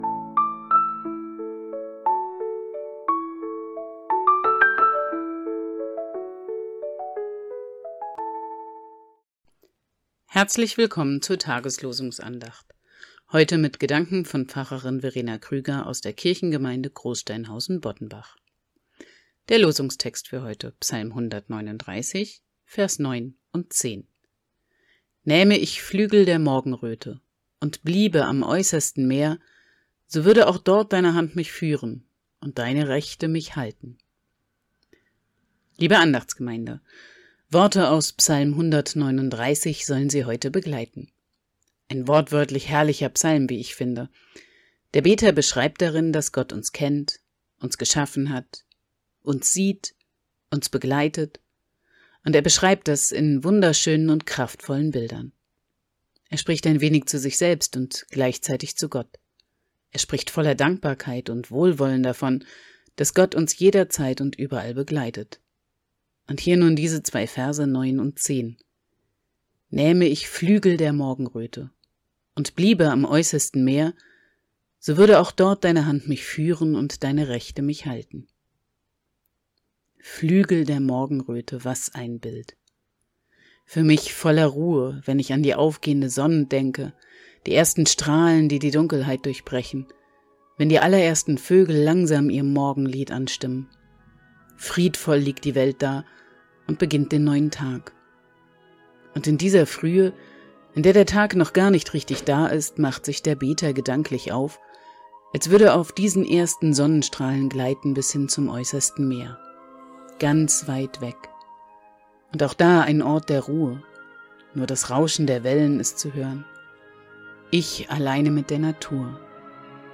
Losungsandacht für Mittwoch, 14.05.2025